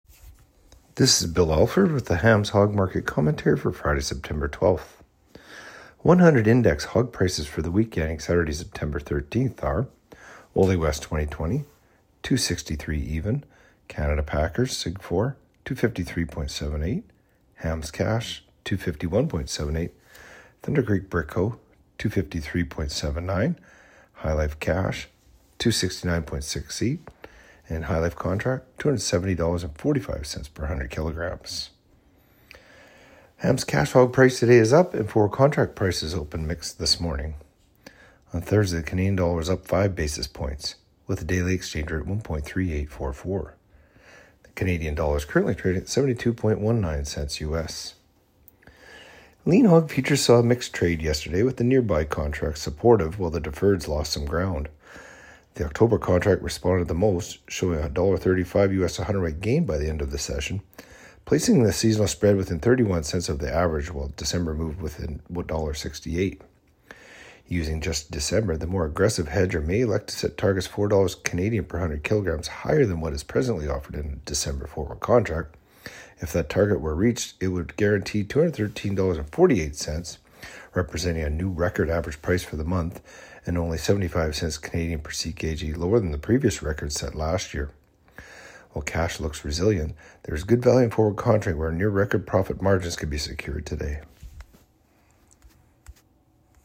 Hog-Market-Commentary-Sep.-12-25.mp3